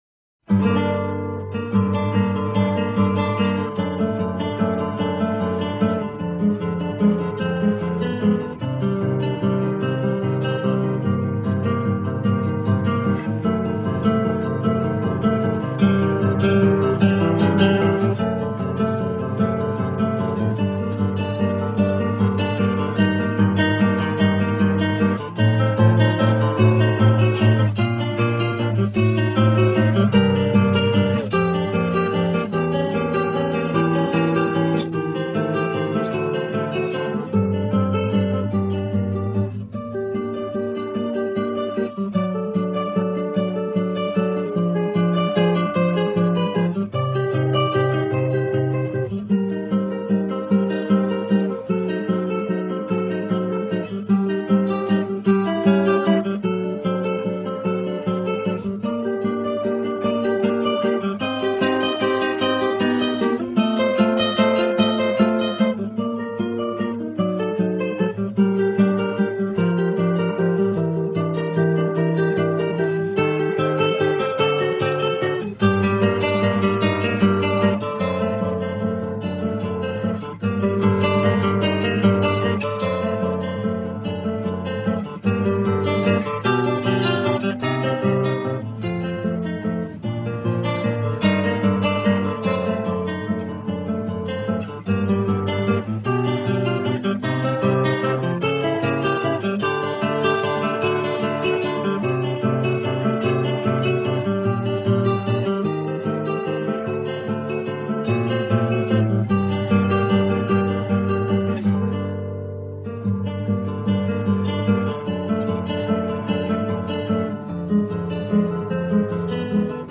Tuning: EADGBE Key:G minor
guitarist